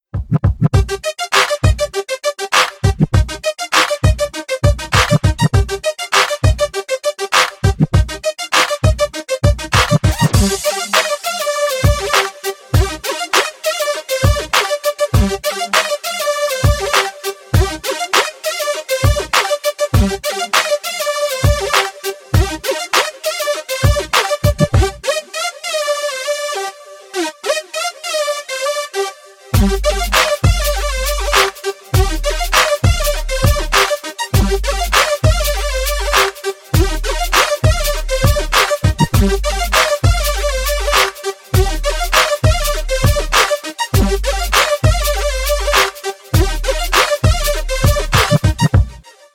• Качество: 320, Stereo
dance
без слов
club